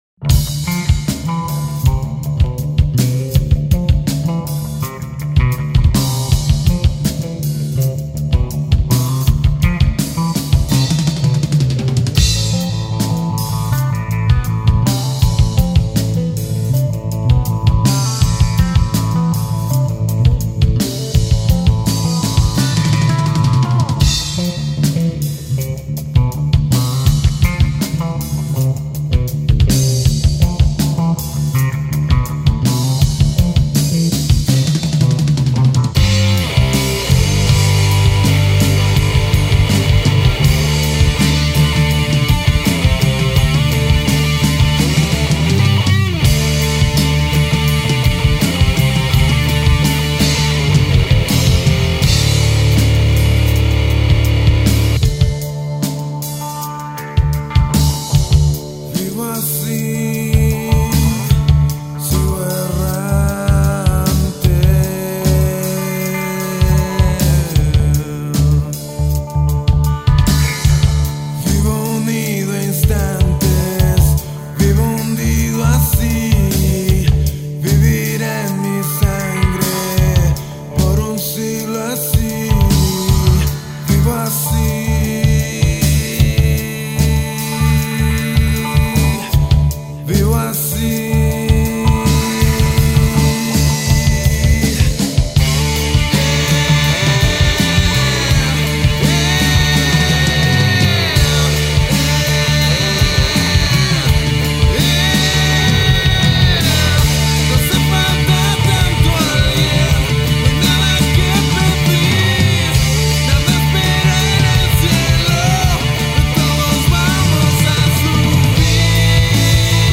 Las canciones se grabaron en diferentes estudios